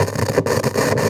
radio_tv_electronic_static_11.wav